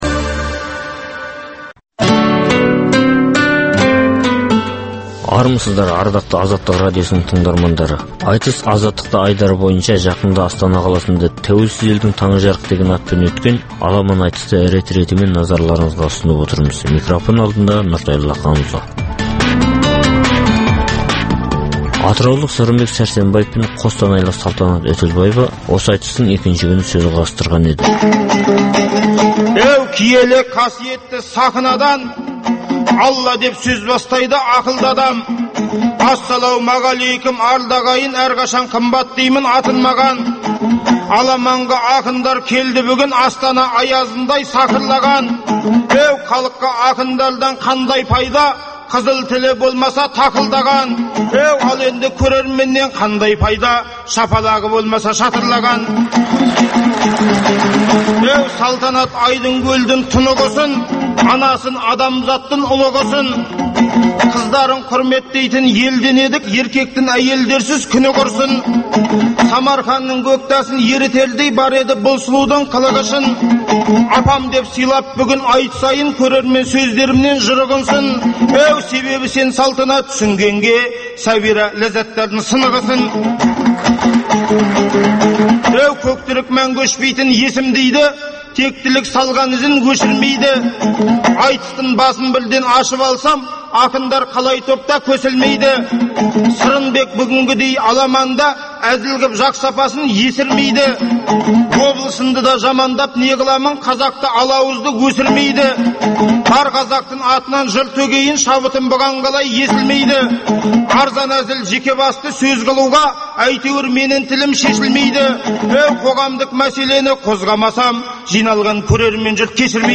Қазақстанда әр уақытта өткізілетін ақындар айтысының толық нұсқасын ұсынамыз.